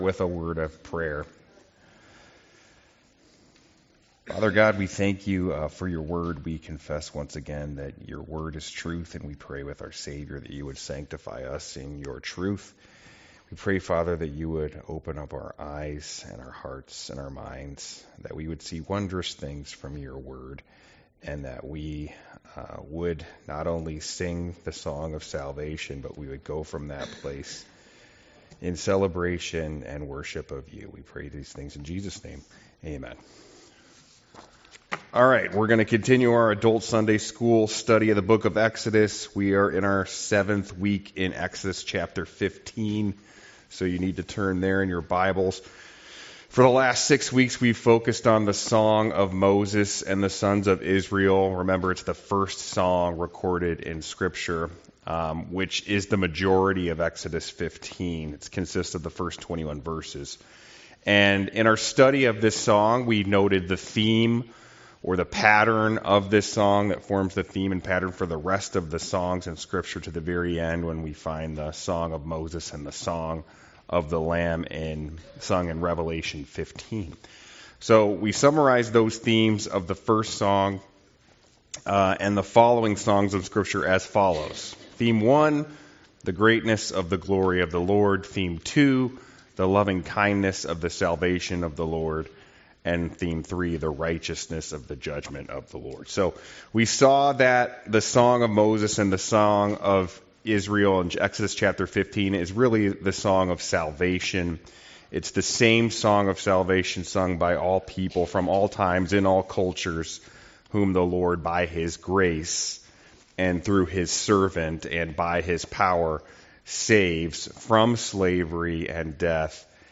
Passage: Exodus 15 Service Type: Sunday School